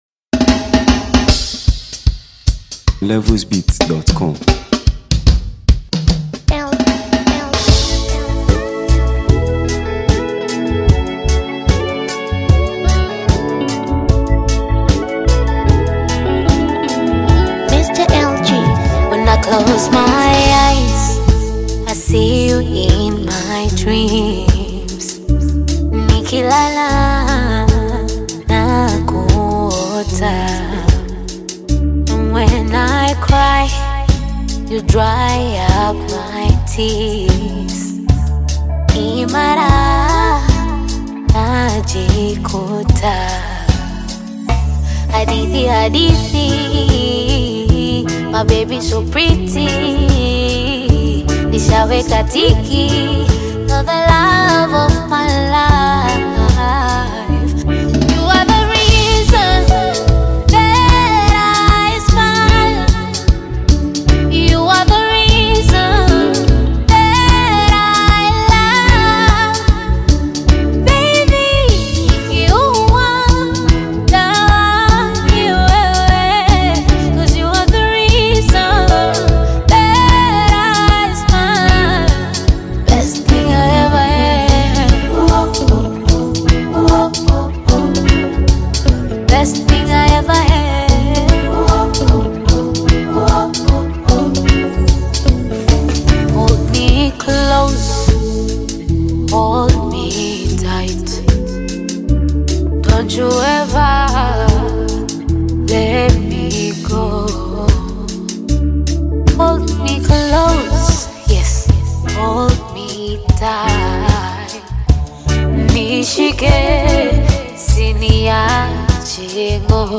Tanzania Music 2025 3:32
Tanzanian sensational female singer-songwriter
blends melody, emotion, and lyrical finesse